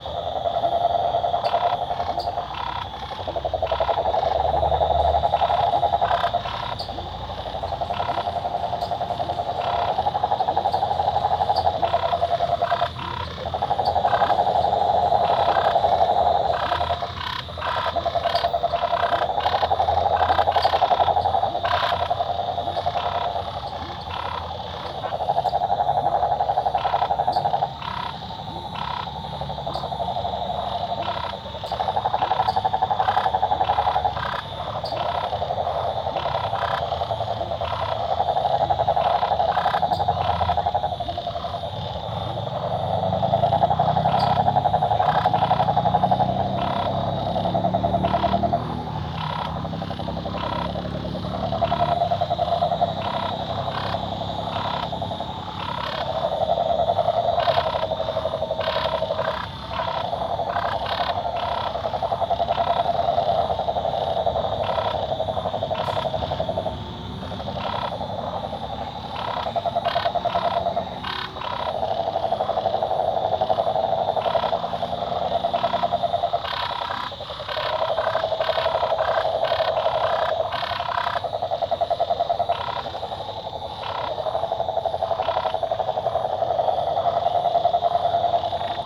Sapos bem perto a noite com grilos, cigarras e passa uma moto Cigarra , Grilo , Moto , Noite , Parque ecológico , Rua , Sapo Goiânia , Parque Beija-Flor Stereo
CSC-06-032-LE - Sapos bem perto a noite com grilos, cigarras e passa uma moto.wav